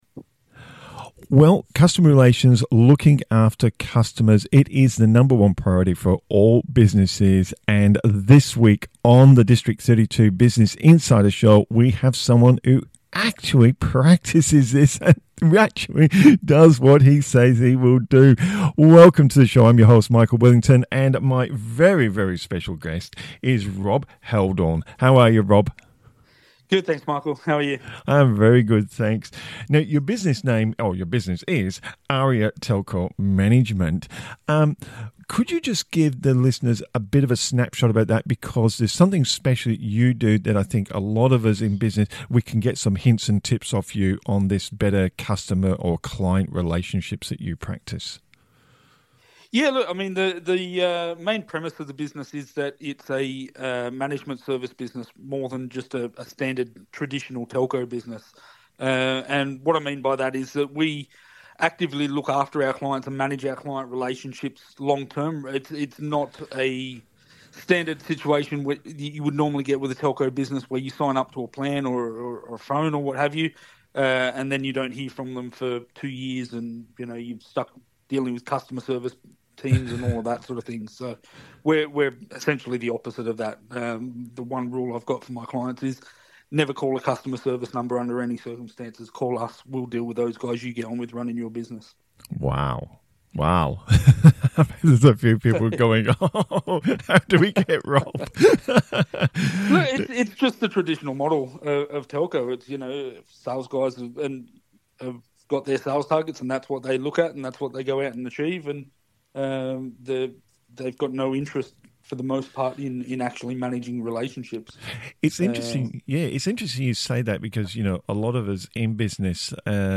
Customer Relationships That Work: A Conversation